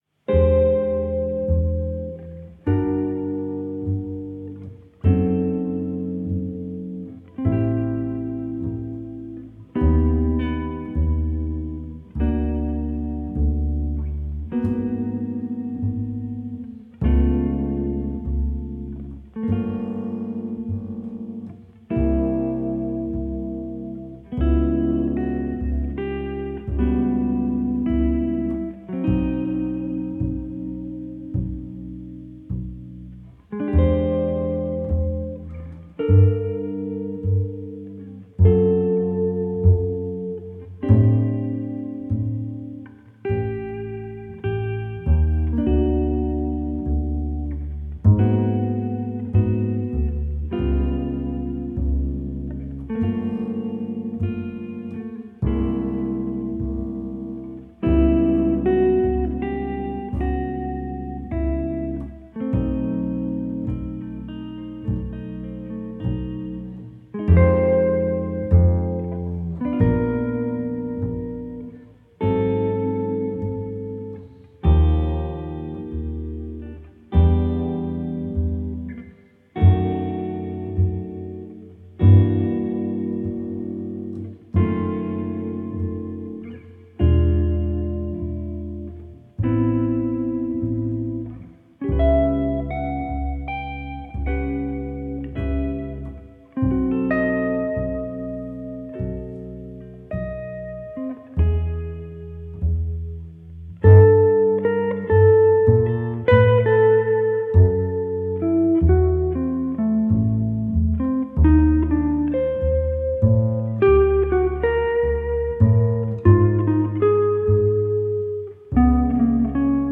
double bass
guitar